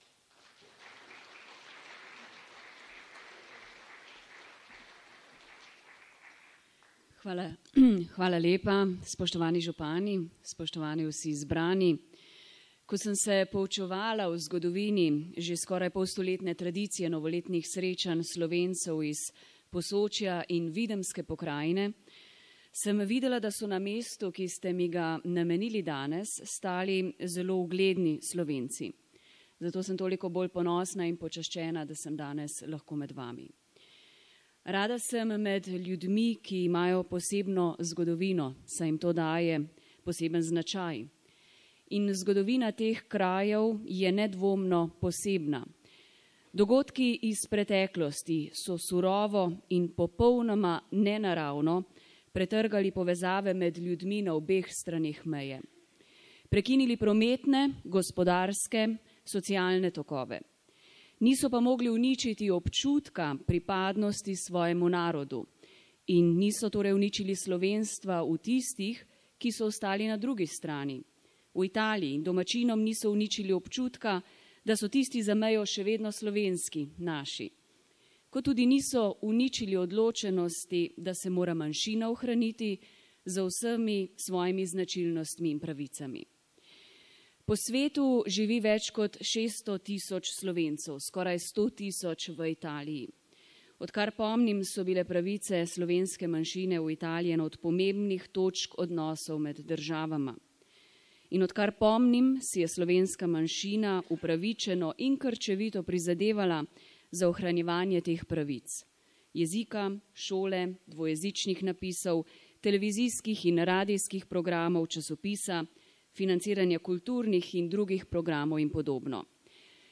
V Kulturnem domu Bovec se je letos odvilo še eno od tradicionalnih srečanj,  ki ohranjajo in poglabljajo odnose med Slovenci  Posočja in Videmske pokrajine.
Slavnostna govornica, evropska poslanka Tanja Fajon je ta, danes brezmejni prostor postavila za zgled izpolnjevanja ideje Evropske unije:
CELOTEN GOVOR EVROPSKE POSLANKE TANJE FAJON
TONSKI POSNETEKGOVORA EVROPSKE POSLANKE TANJE FAJON